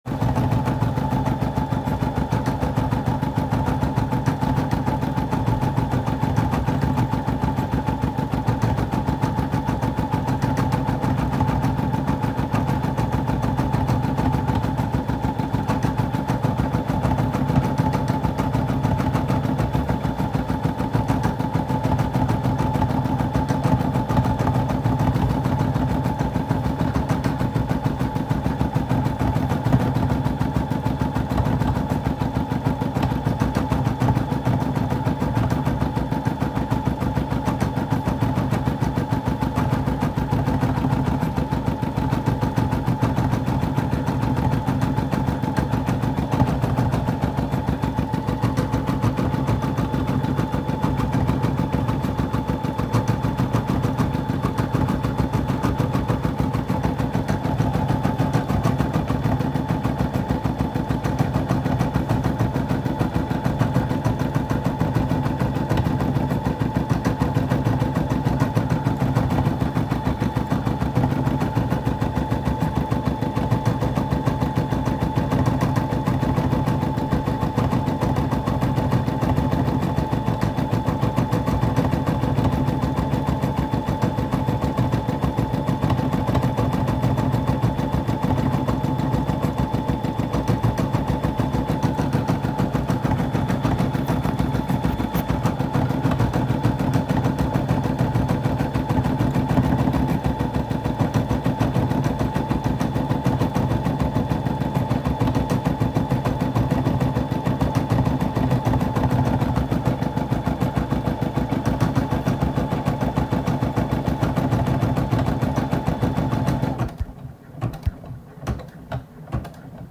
Layer 1: The Voice Layer 2: The Keyboard Layer 3: The Machine Layer 4: The Hand and the Tools Individual audio layers recorded from ideation to the finished work.